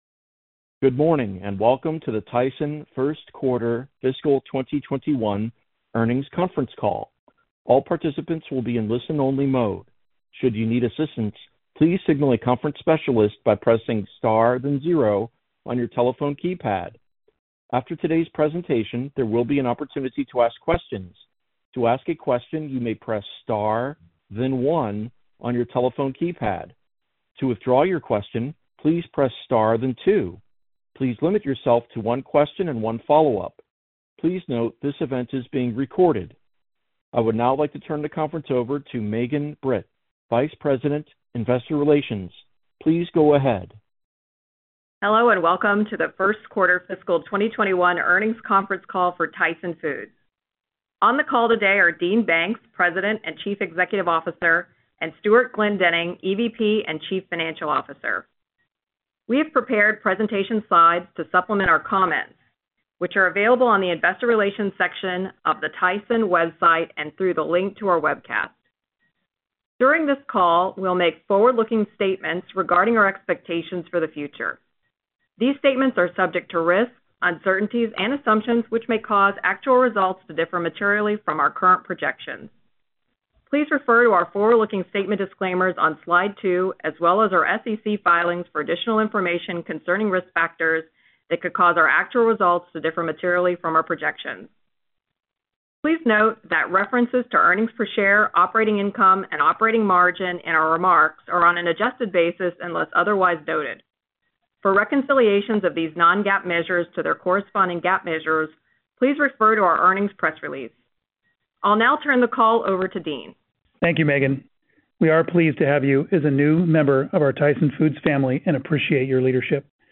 Tyson Foods Inc. - Q1 2021 Tyson Foods Earnings Conference Call